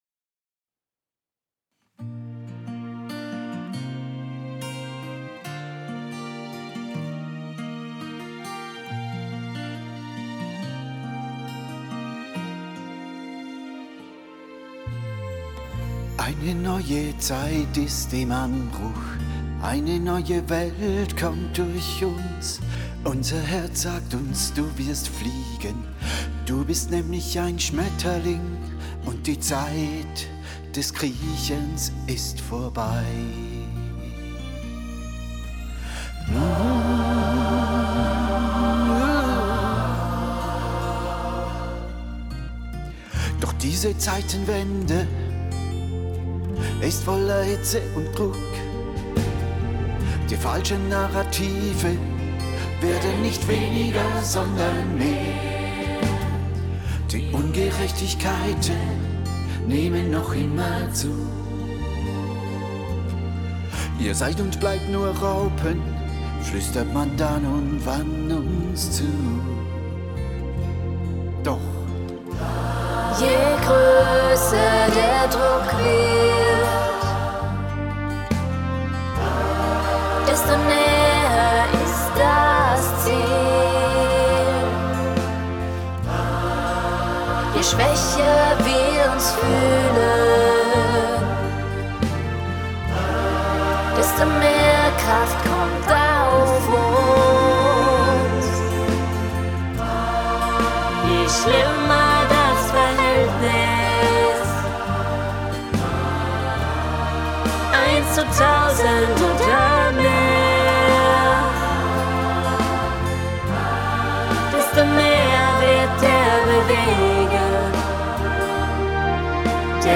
Freundestreffen 2025